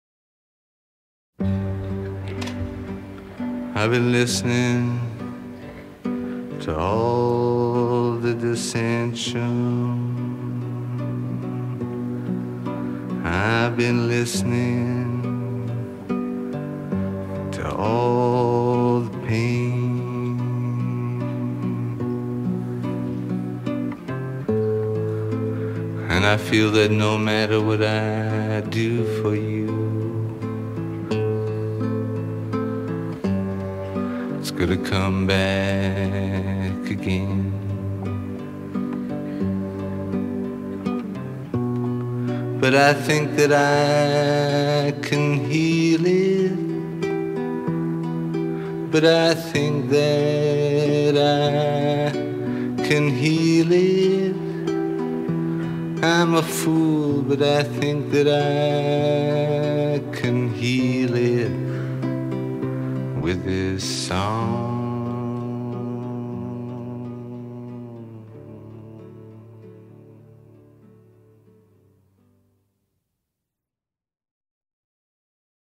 Folk, Live